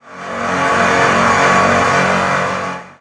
saw_loop2f.wav